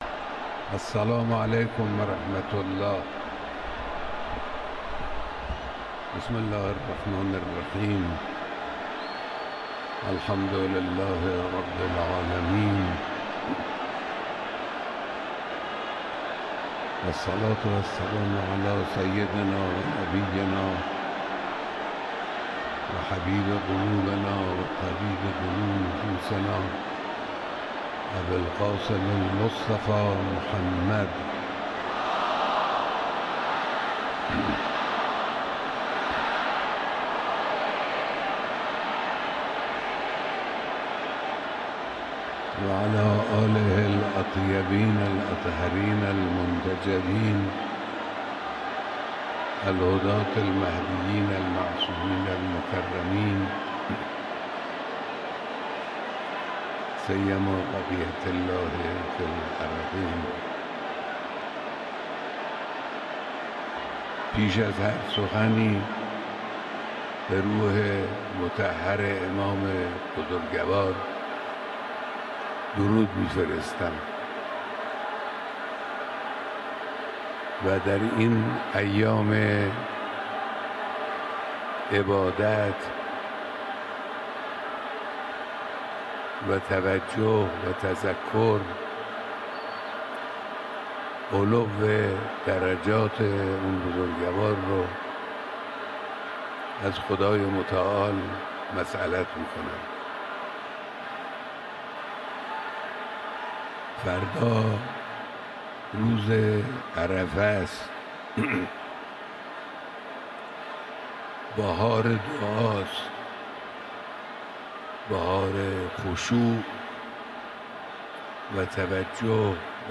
سخنرانی رهبر معظم انقلاب اسلامی
در مراسم سی و ششمین سالگرد ارتحال حضرت امام خمینی (قدس سره)